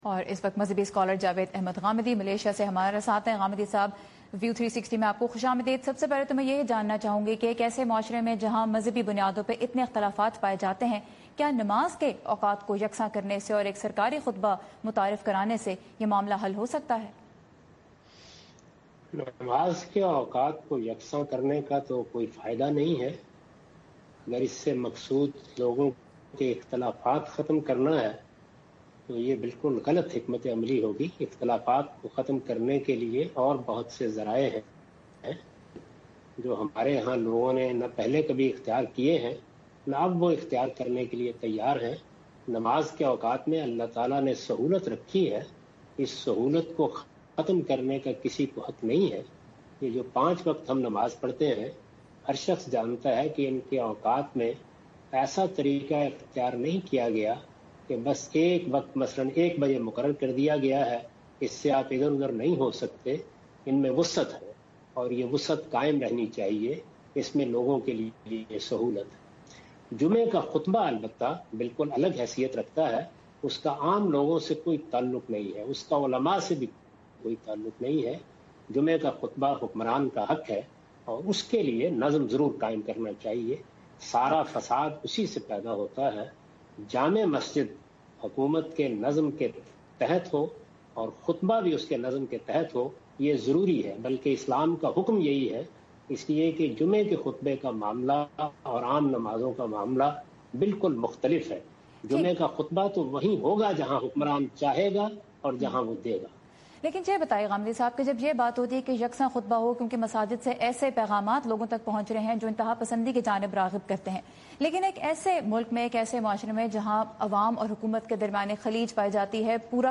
A short interview by Javed Ahmad Ghamidi to Voice of America wherein he discusses whether rostrum of mosque is the jurisdiction of government or Islamic scholars.